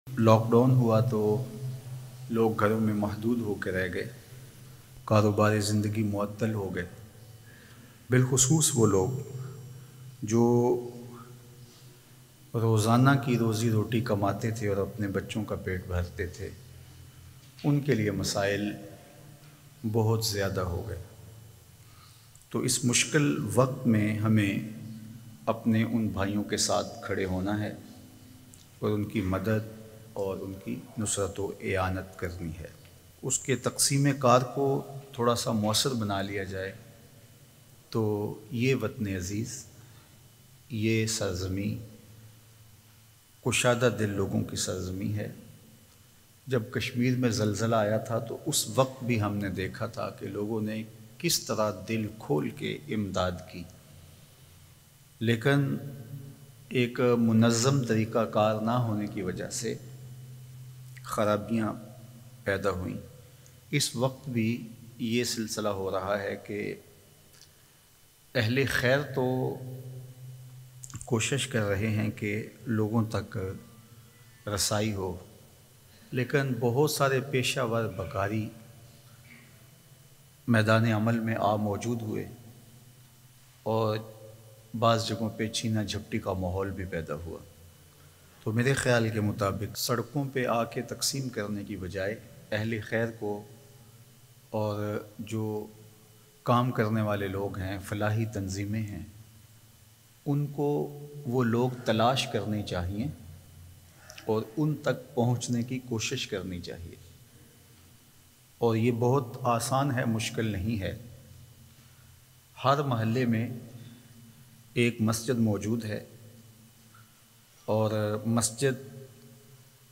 MP3 Bayan